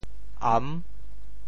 am6.mp3